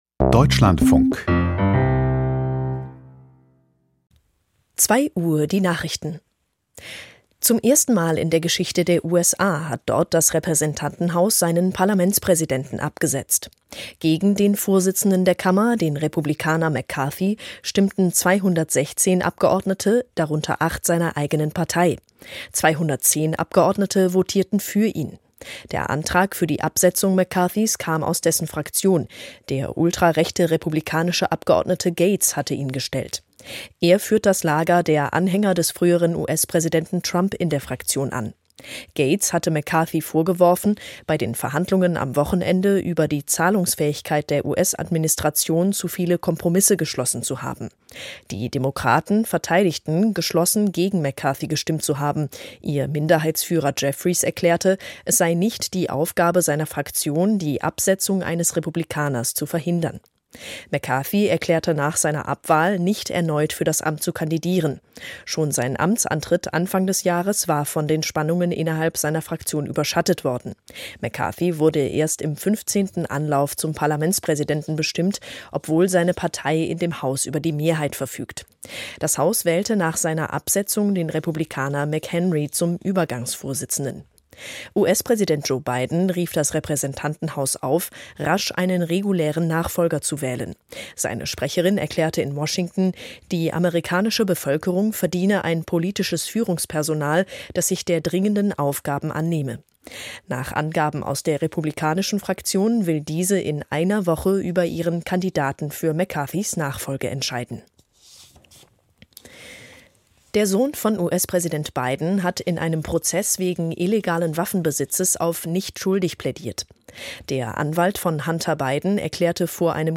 Die Nachrichten ➕ Abonneren ➕ Ondertiteling ✔ Abonneren ✔ Geabonneerd Spelen Spelen Delen Markeer allemaal (on)gespeeld ...